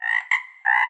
animalia_frog_1.ogg